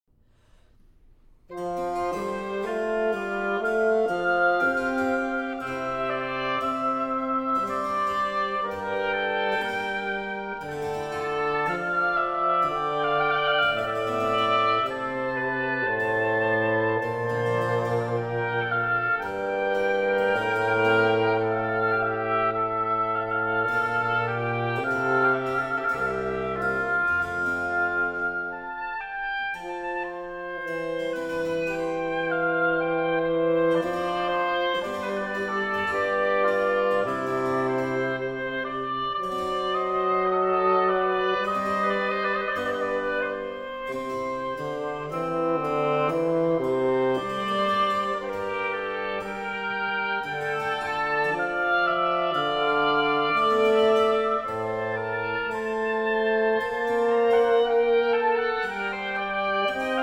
Oboe
Bassoon
Harpsichord
from Trio Sonata in D Minor